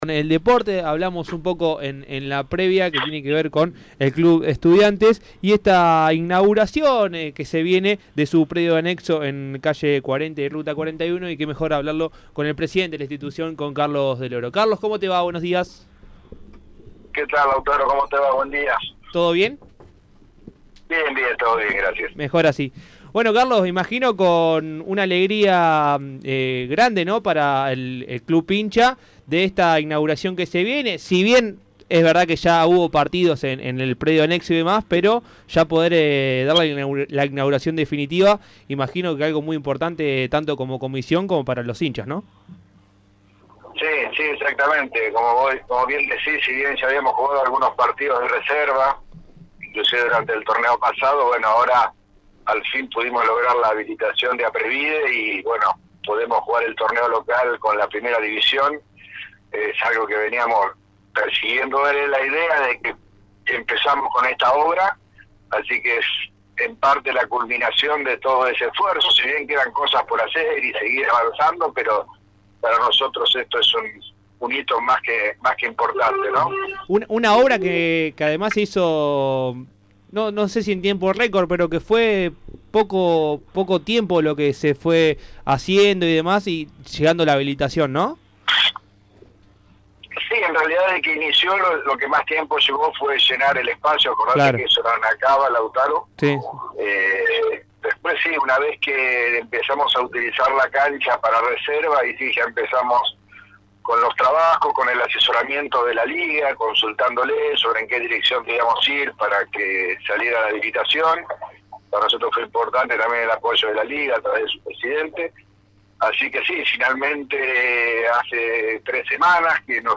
pasó por los micrófonos de Dato Posta Radio